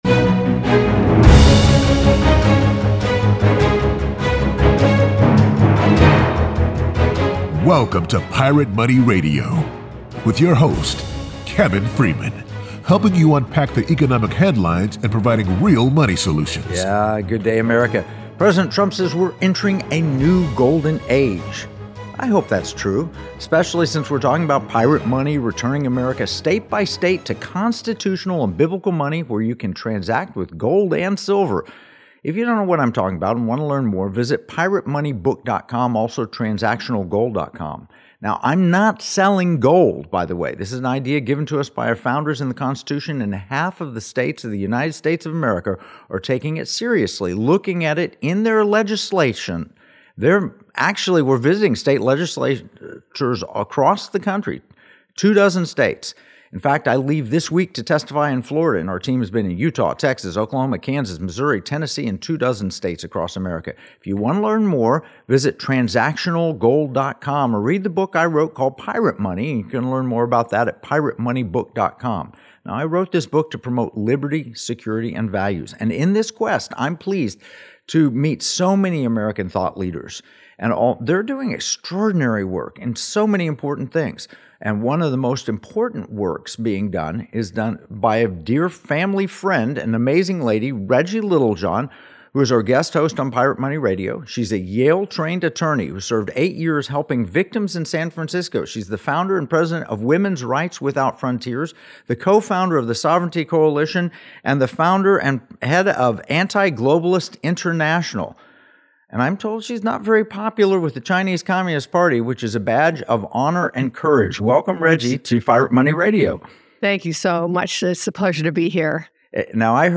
Unlocking the Truth Behind China's One-Child Policy | Guest